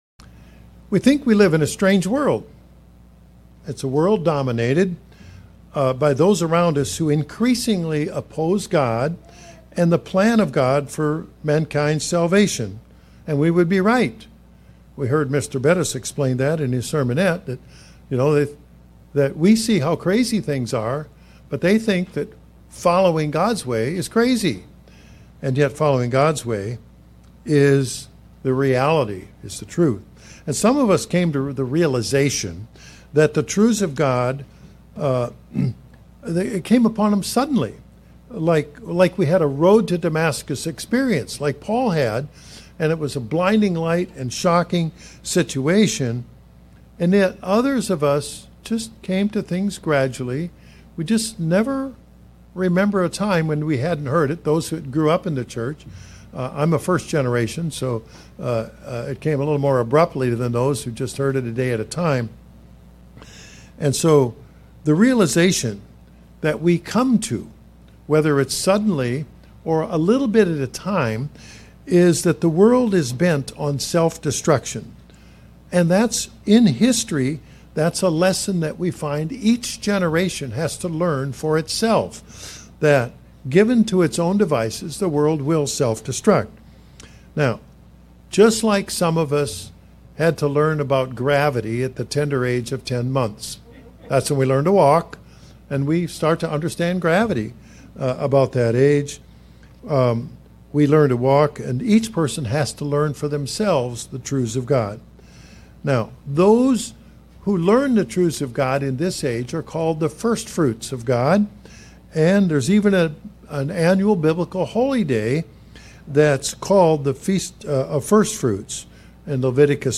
Sermons
Given in Springfield, MO